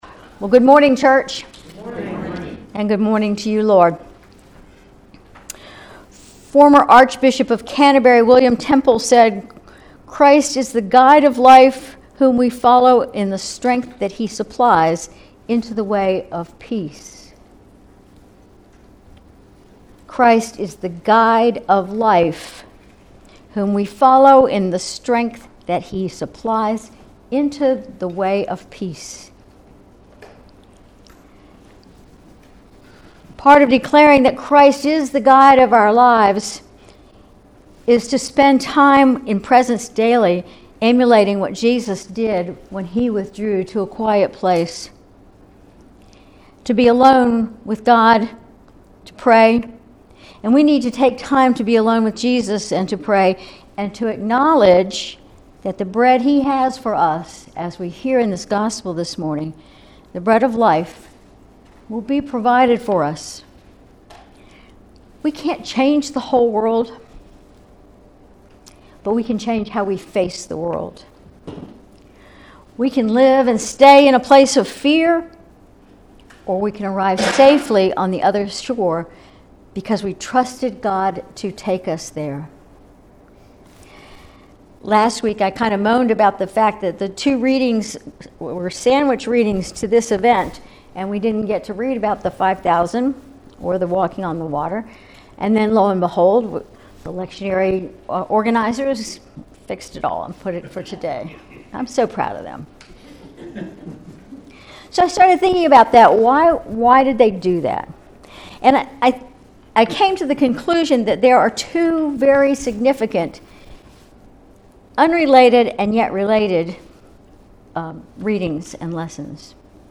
Sermon July 28, 2024